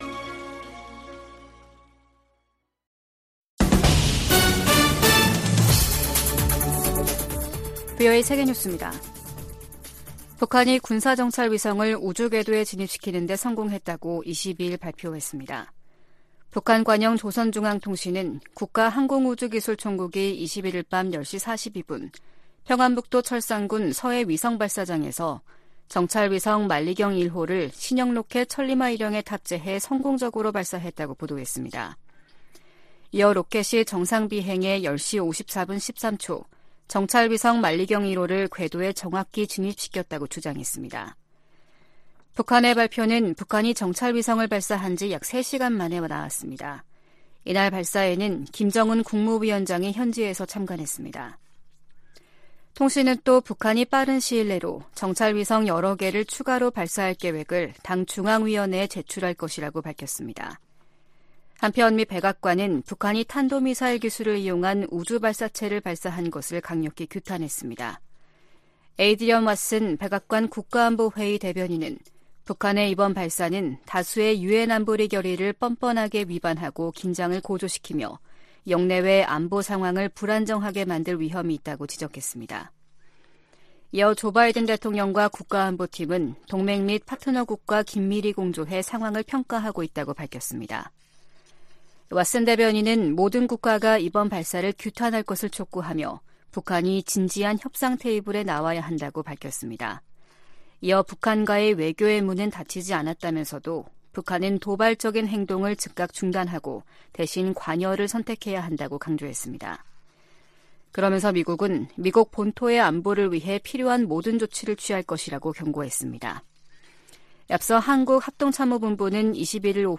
VOA 한국어 아침 뉴스 프로그램 '워싱턴 뉴스 광장' 2023년 11월 22일 방송입니다. 국제해사기구(IMO)는 북한이 오는 22일부터 내달 1일 사이 인공위성 발사 계획을 통보했다고 확인했습니다. 북한의 군사정찰위성 발사 계획에 대해 미 국무부는 러시아의 기술이 이전될 가능성을 지적했습니다. 한국 정부가 남북 군사합의 효력 정지를 시사하고 있는 가운데 미국 전문가들은 합의 폐기보다는 중단했던 훈련과 정찰 활동을 재개하는 편이 낫다고 진단했습니다.